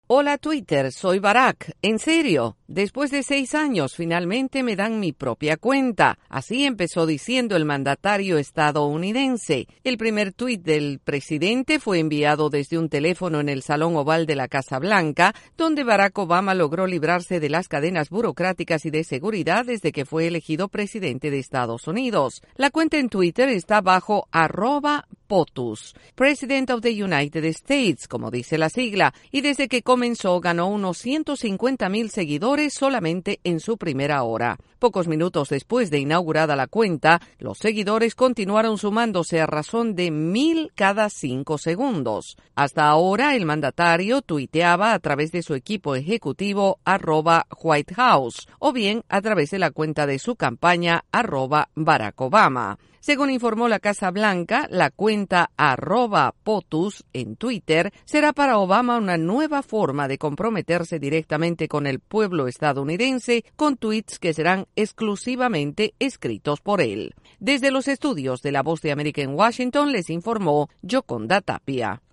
La Casa Blanca muestra gran satisfacción por la respuesta a la cuenta en twitter del presidente Barack Obama. El informe desde la Voz de América en Washington